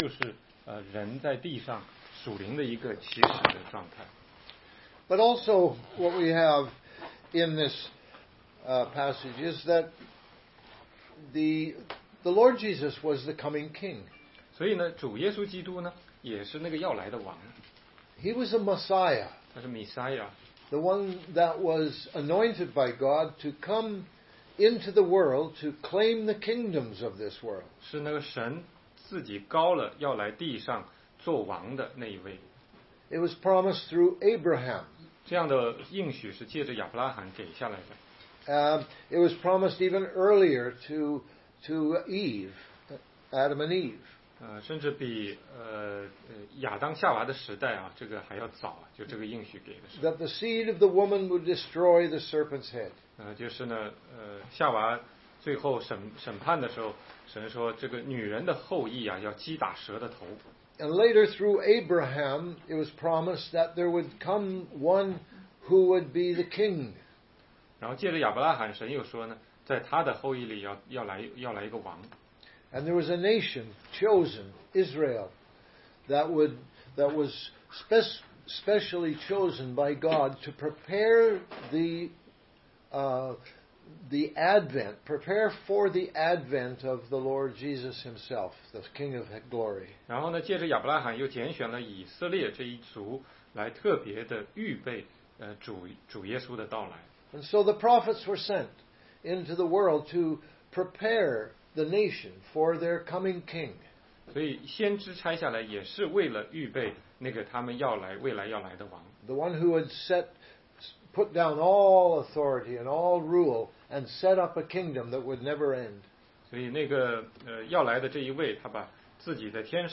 16街讲道录音 - 重读约翰福音9章